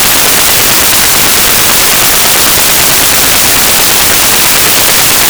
2m Störer, unbekanntes Signal?
Nach der Rückkehr aus dem Urlaub habe ich zwischen 144,2 und 144,8 MHz ein AFSK, PSK, QPSK bzw. was auch immer Signal. Es ist horizontal polarisiert und erreicht S9+40 dB aus 45° in JO31GH.